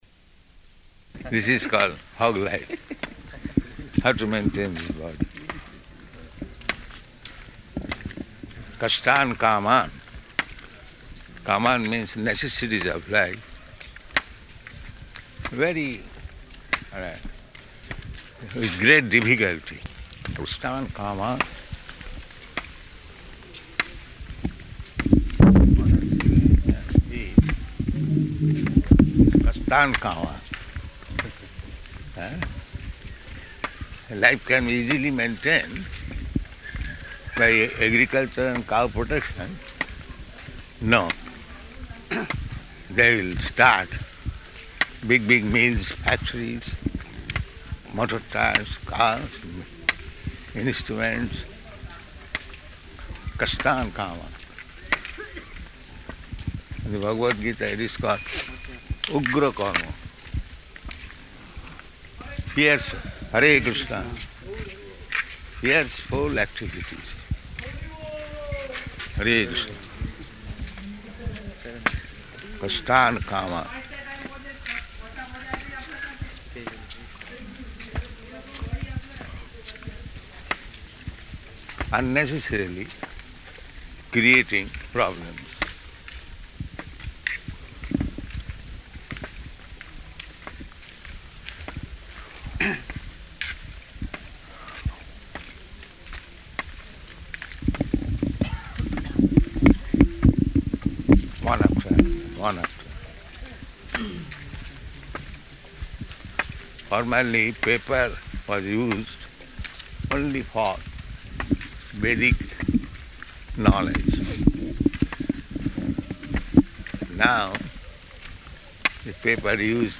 Morning Walk --:-- --:-- Type: Walk Dated: September 13th 1975 Location: Vṛndāvana Audio file: 750913MW.VRN.mp3 Prabhupāda: This is called hog life, how to maintain this body.